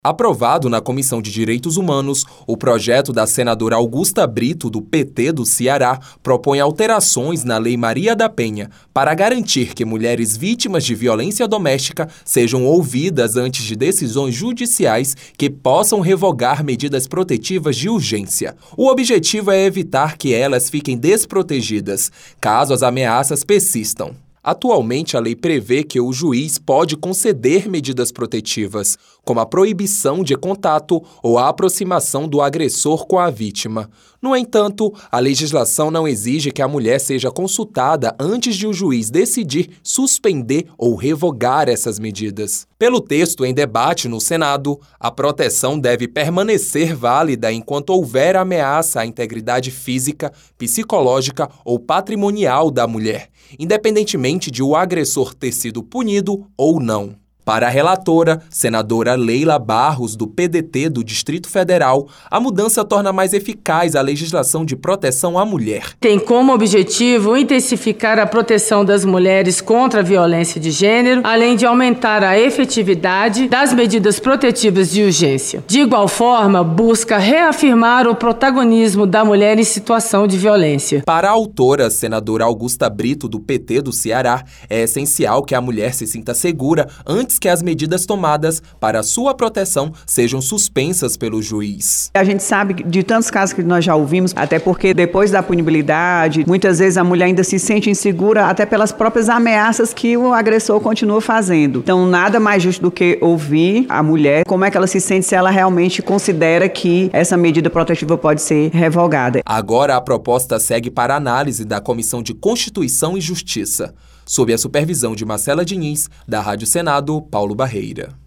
Transcrição